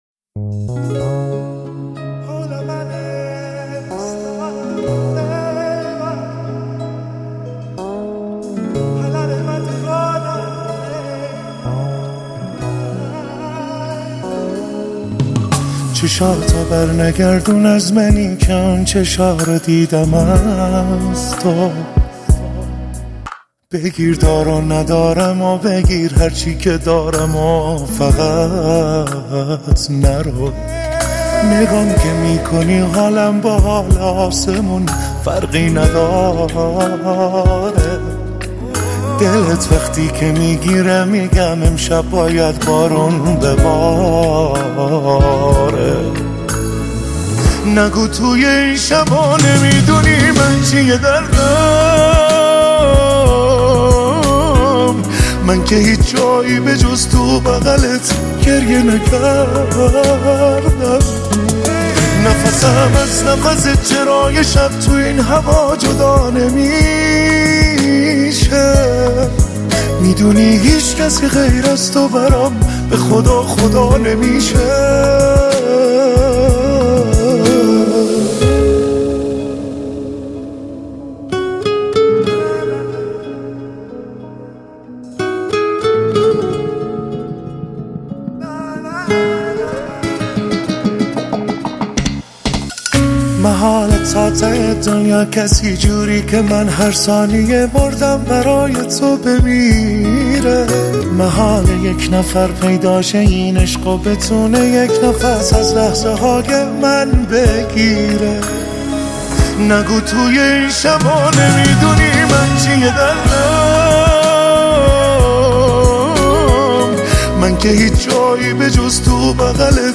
متن آهنگ غمگین.